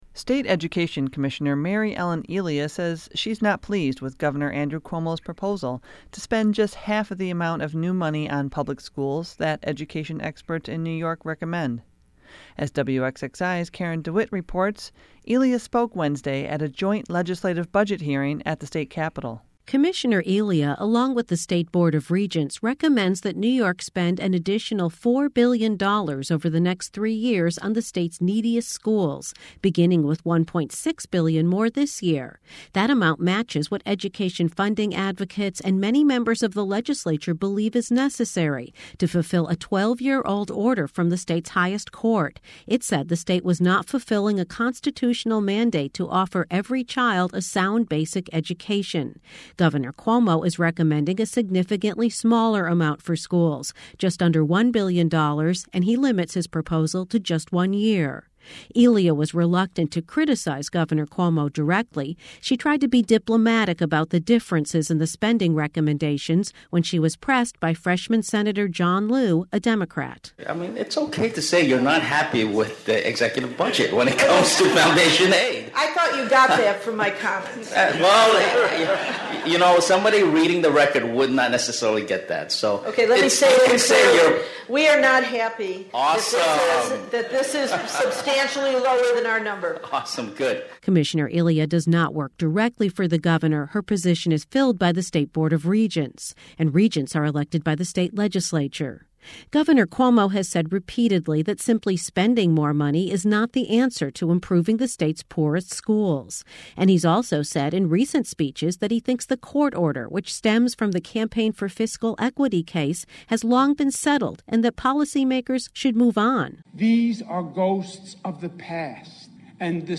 State Education Commissioner Mary Ellen Elia said she’s not pleased with Gov. Andrew Cuomo’s proposal to spend just half of the amount of new money on public schools that education experts in New York recommend. She spoke Wednesday at a joint legislative budget hearing at the state Capitol.
ed_hearing_long_with_intro_2-6.mp3